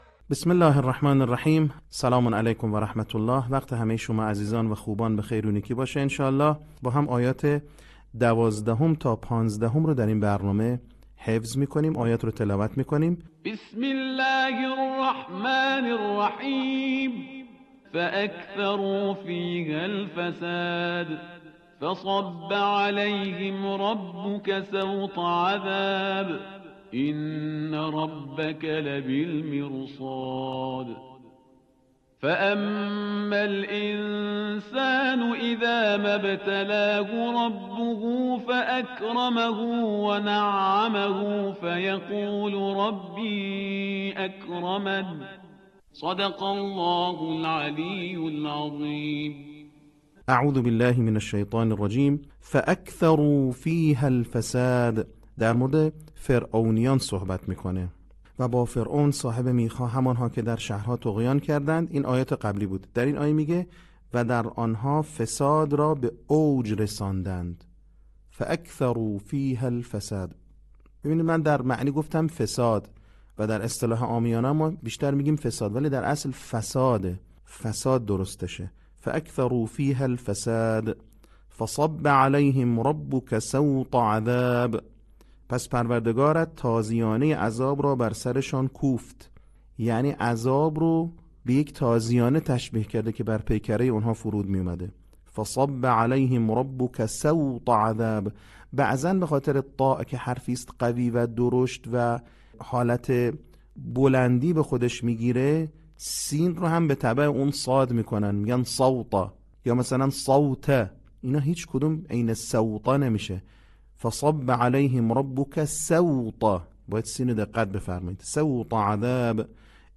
صوت | بخش سوم آموزش حفظ سوره فجر